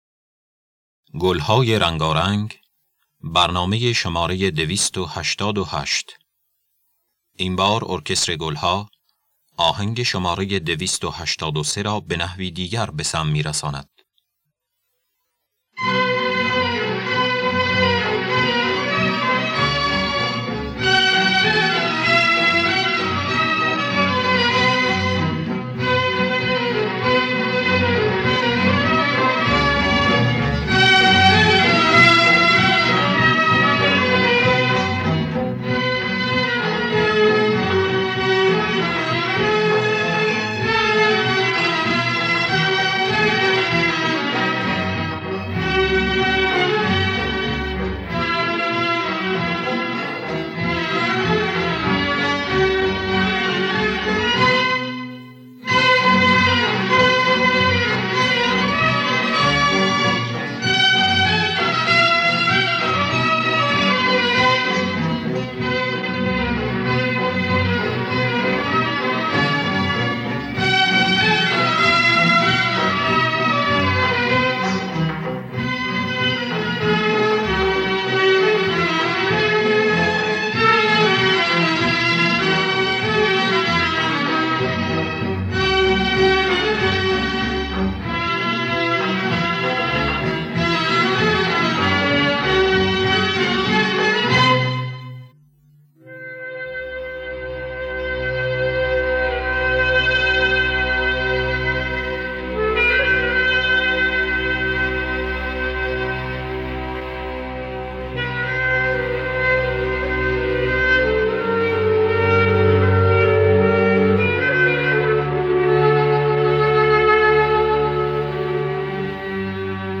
گلهای رنگارنگ ۲۸۸ - ماهور
خوانندگان: الهه عبدالوهاب شهیدی نوازندگان: جواد معروفی